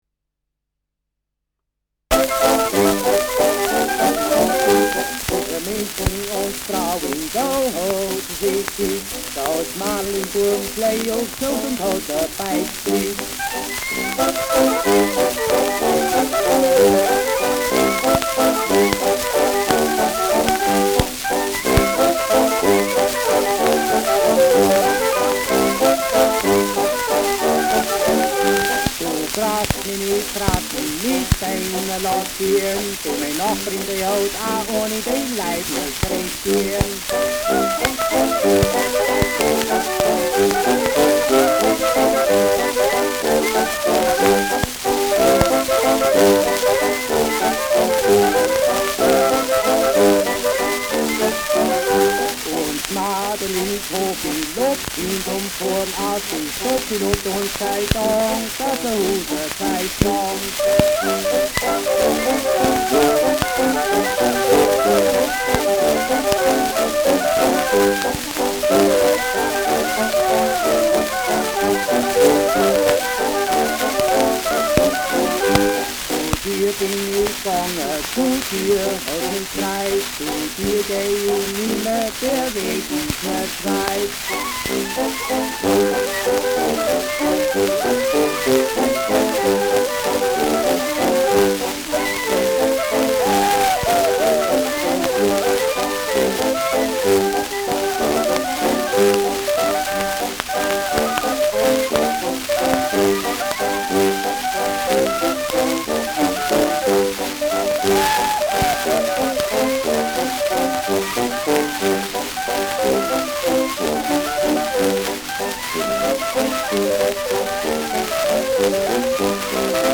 Zwischen München und Straubing : Ländler mit Gesang
Schellackplatte
wegen Bruchs fehlender Anfang : präsentes Rauschen : Knacken
Kapelle Die Alten, Alfeld (Interpretation)
Etikett: Walhalla-Record : Zwischen München und Straubing : Ländler mit Gesang : „Die Alten“ : Mittelfränkische Bauernkapelle : Alfeld : 2129 Ar.